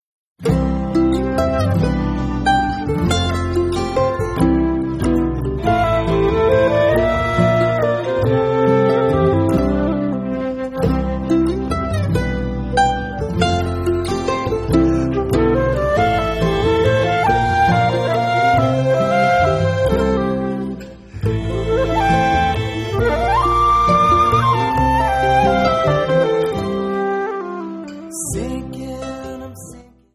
High-flying bluegrass from California!
Acoustic
Americana
Bluegrass
Jamband